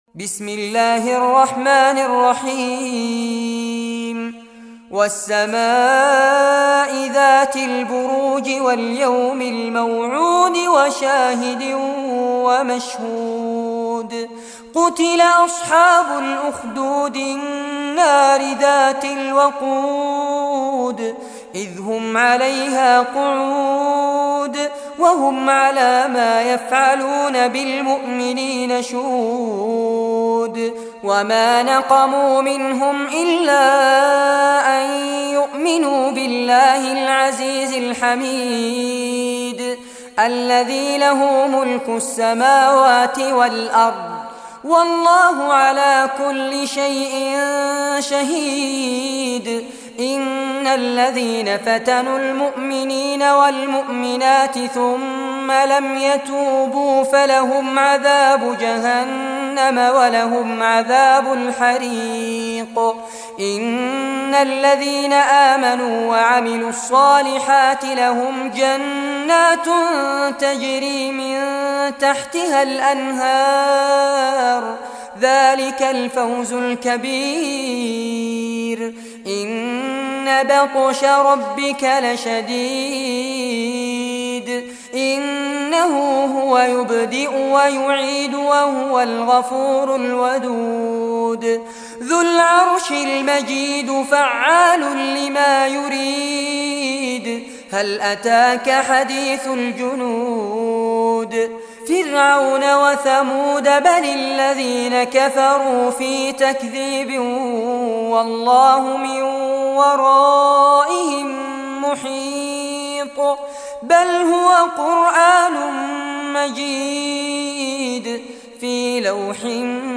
Surah Al-Buruj Recitation by Fares Abbad
Surah Al-Buruj, listen or play online mp3 tilawat / recitation in Arabic in the beautiful voice of Sheikh Fares Abbad.
85-surah-burooj.mp3